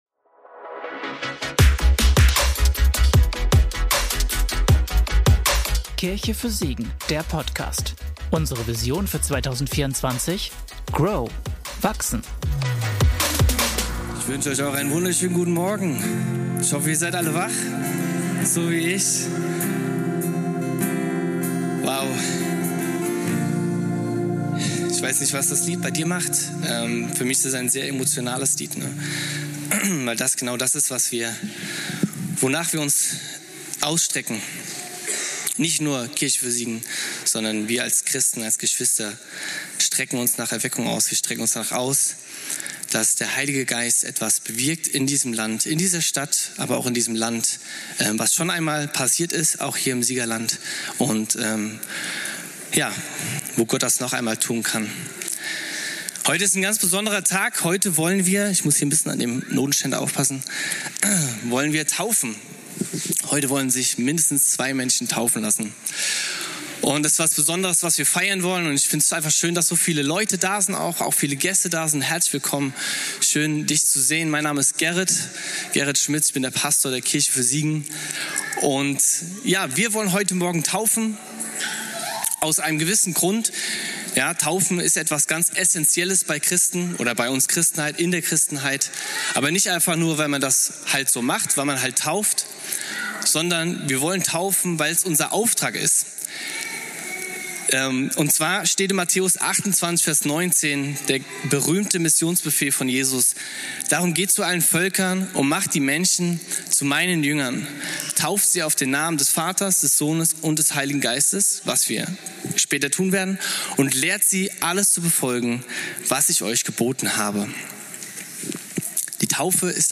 Taufgottesdienst am 09.06.2024